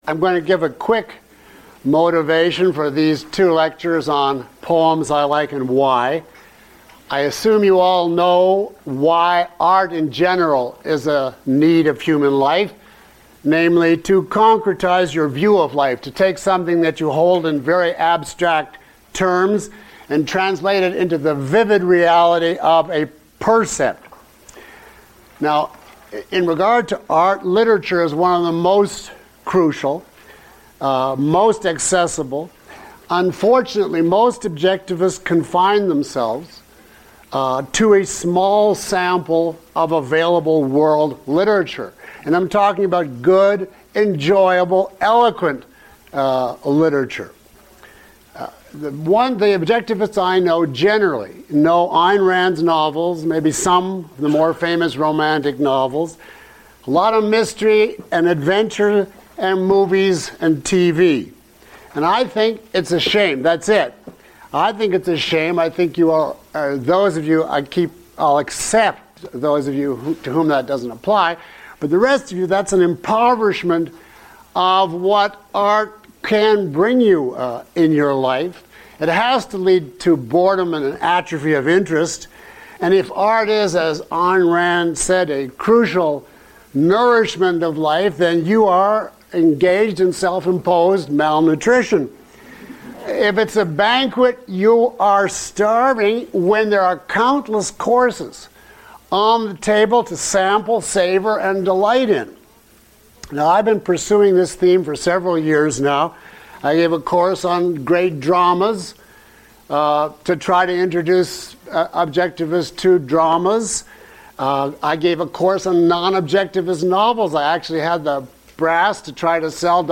He uses examples from some of his favorite poetry—read aloud in the lecture—to demonstrate how to analyze and understand great poems.